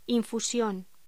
Locución: Infusión
voz